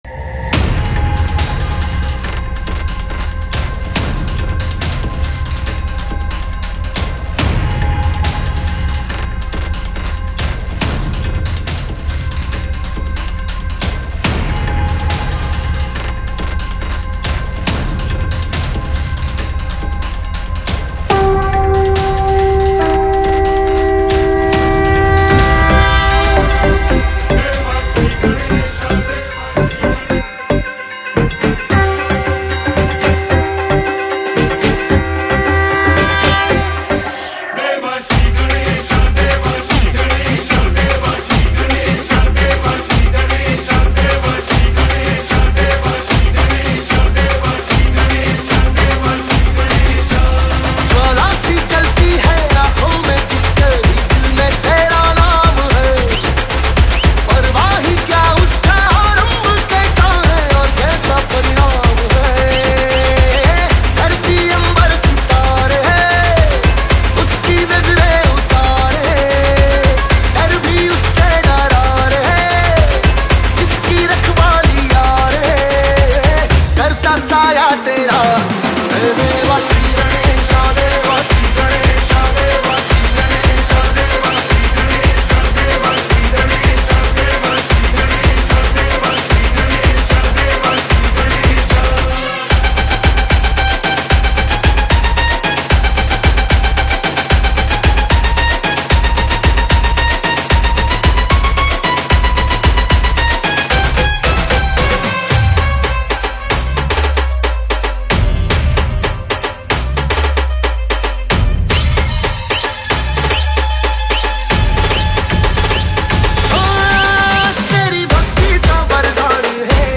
Ganesh Puja Bhakti Dj Remix Songs